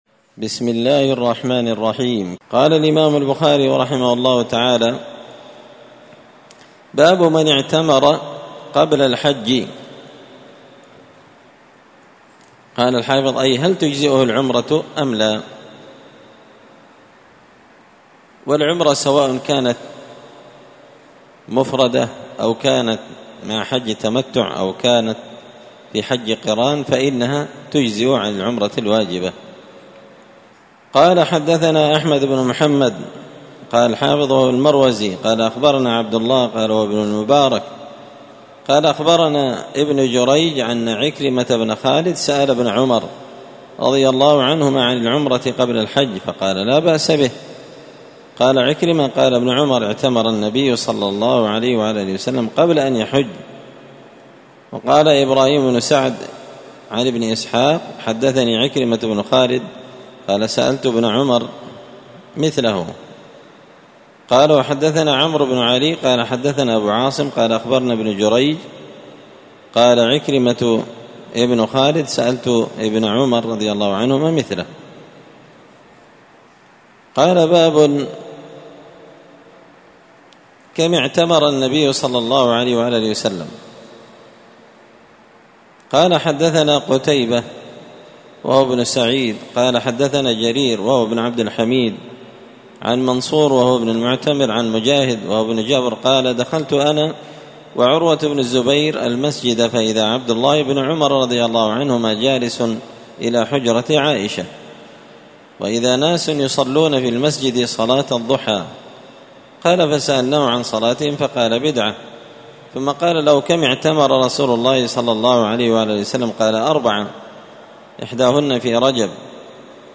كتاب العمرة من شرح صحيح البخاري- الدرس 2 باب من اعتمر قبل الحج